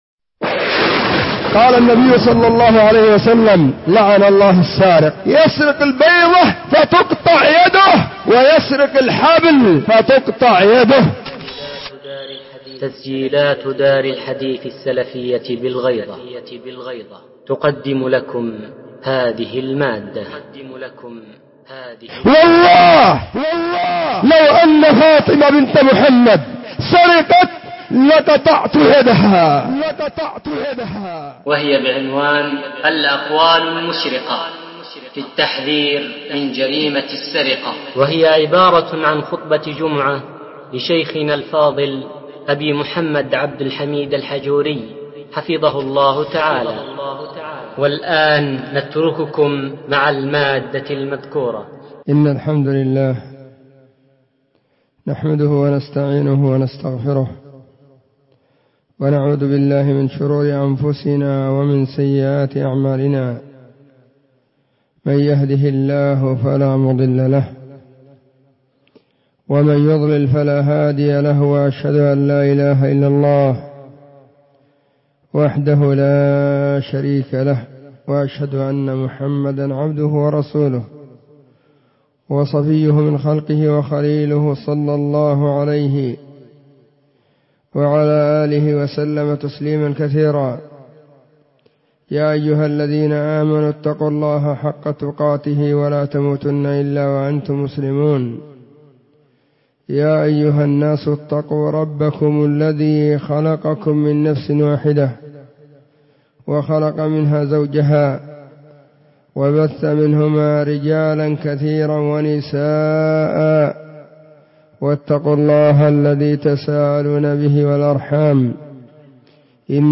خطبة جمعة بعنوان : *الأقوال المشرقة في التحذير من جريمة السرقة*
📢 وكانت في مسجد الصحابة بالغيضة محافظة المهرة – اليمن.